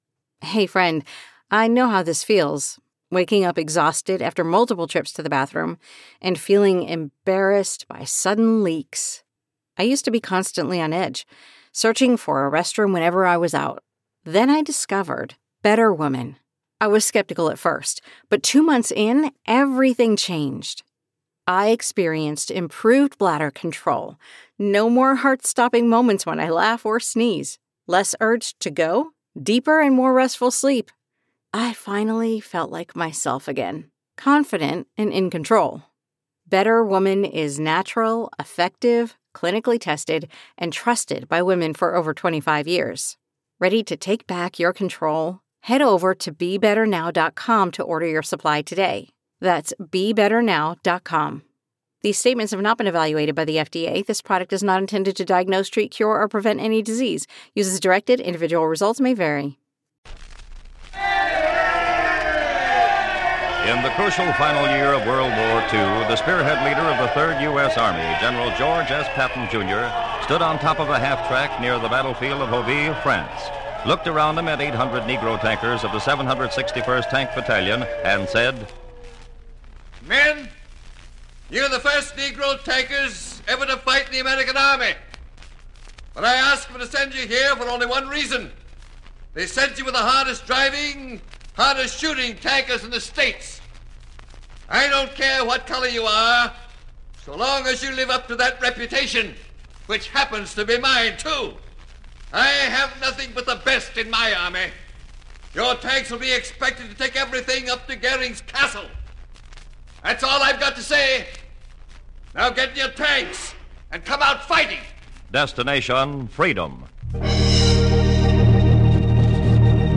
This series was known for its dramatizations of the lives and struggles of notable African Americans, highlighting their contributions to society and the fight against racial discrimination.